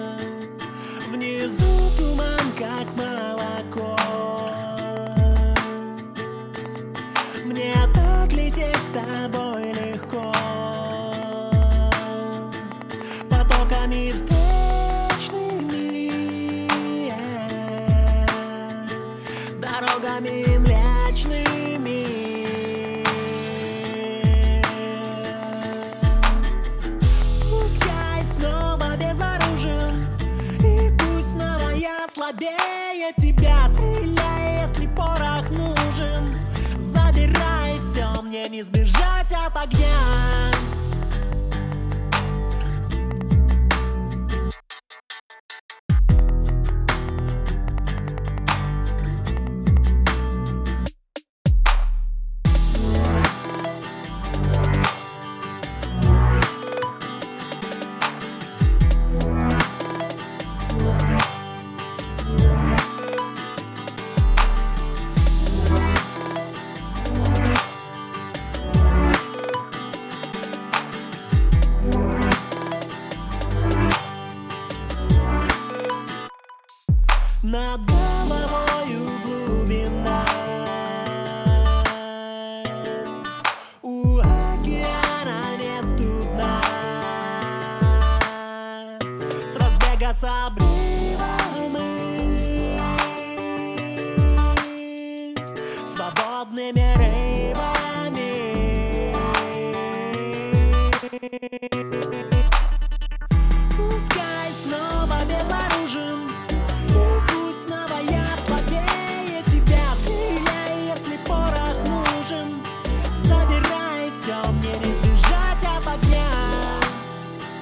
Pop. Urban.
На этом этапе смущают гитары и бочка.